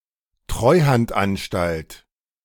The Treuhandanstalt (German: [ˈtʁɔɪ̯hantˌʔanʃtalt]